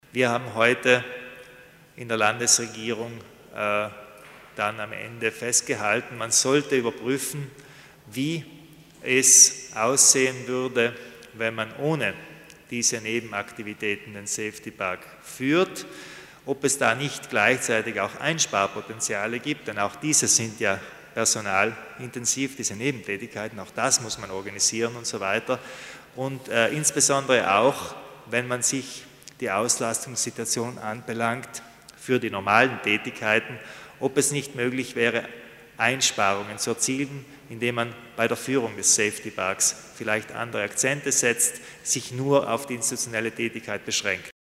Landeshauptmann Kompatscher erläutert die Änderungen, die beim Safety Park vorgenommen werden